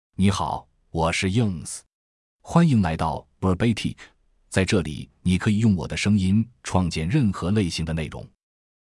YunzeMale Chinese AI voice
Yunze is a male AI voice for Chinese (Mandarin, Simplified).
Voice sample
Listen to Yunze's male Chinese voice.
Male
Yunze delivers clear pronunciation with authentic Mandarin, Simplified Chinese intonation, making your content sound professionally produced.